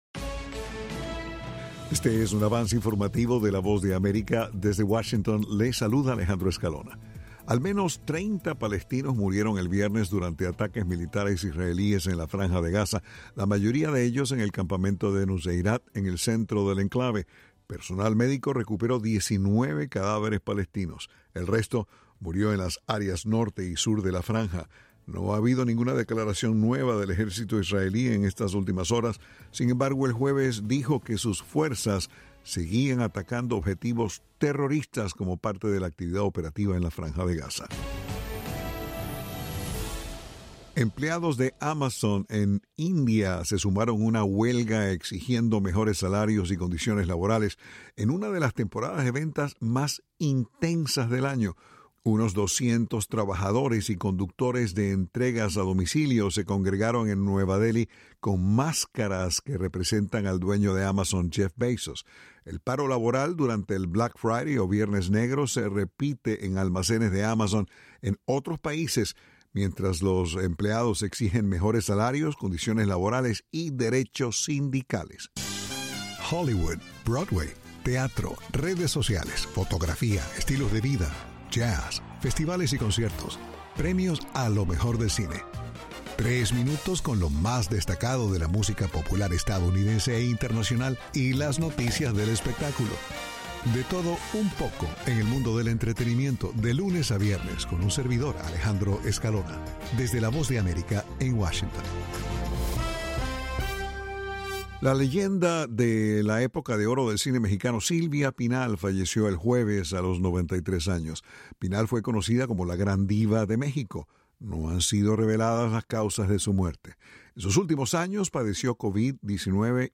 Este es un avance informativo de la Voz de América.